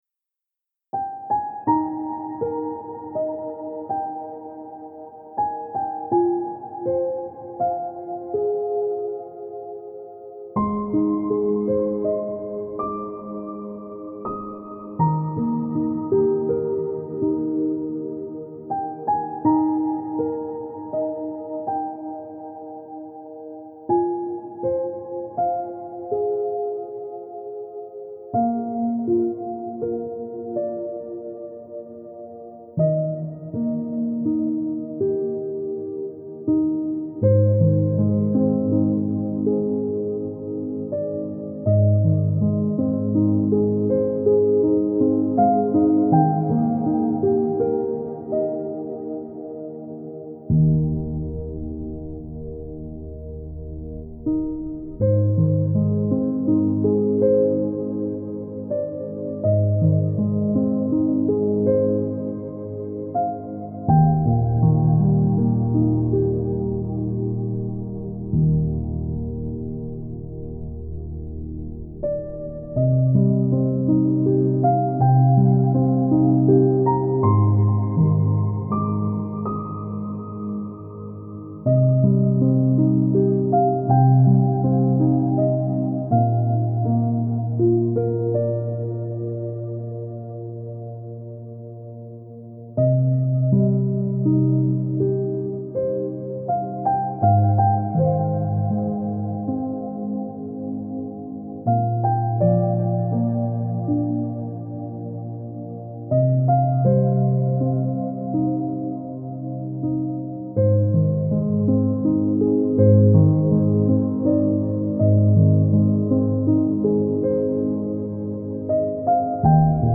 это нежная инструментальная композиция в жанре неоклассики